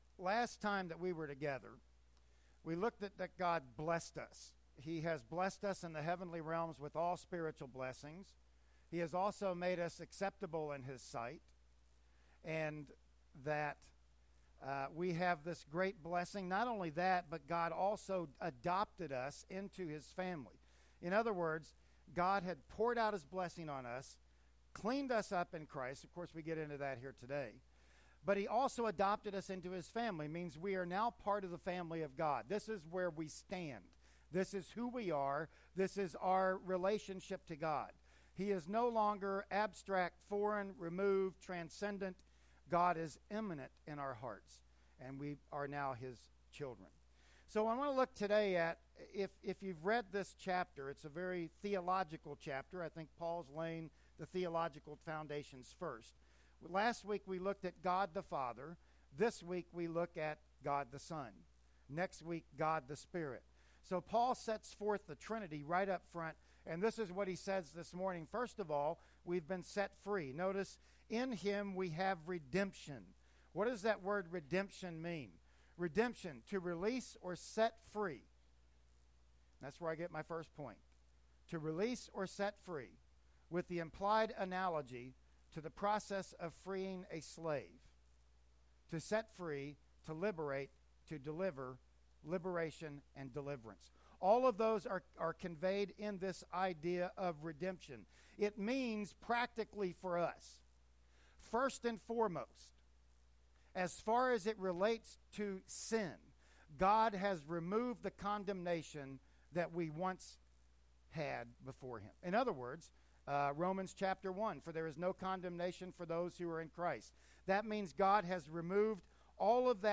"Ephesians 1:7-10" Service Type: Sunday Morning Worship Service Bible Text